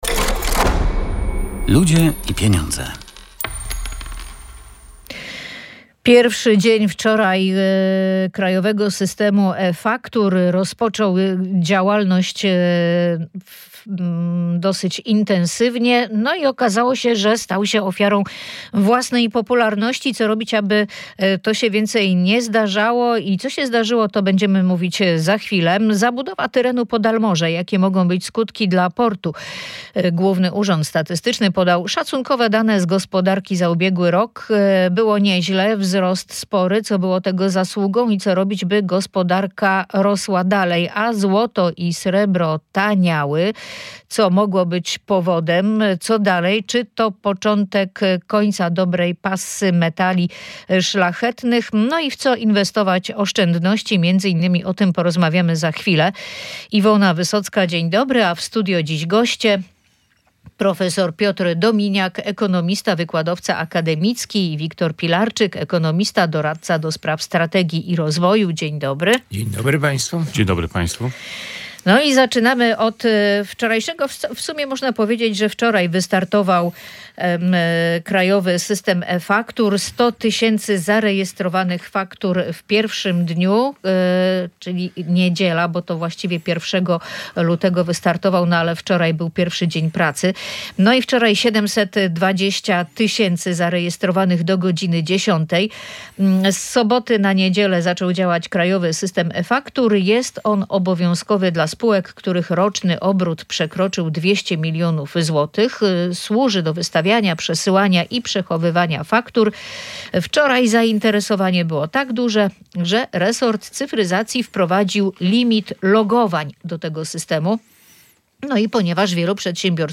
Na ten temat dyskutowali goście audycji „Ludzie i Pieniądze”